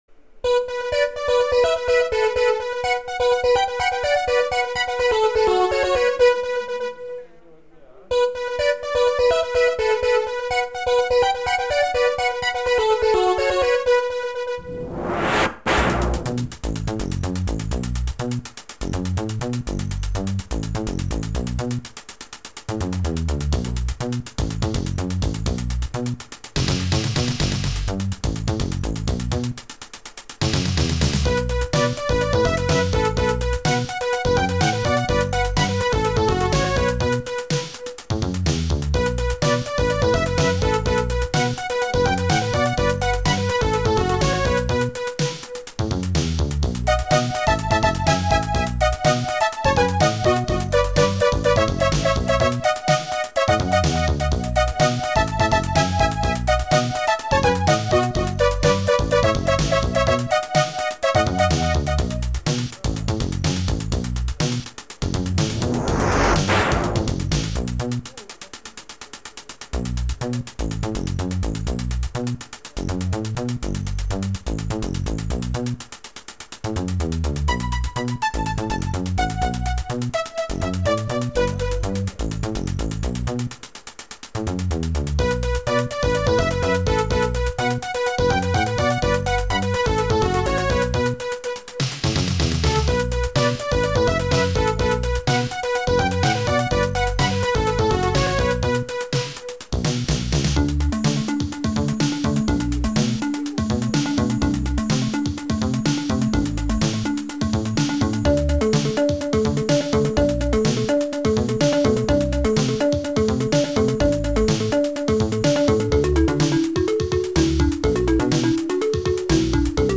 This board is very similar to the sound system of the Amiga computer.
With this board Aleste computer plays .stm (Scream Tracker) files.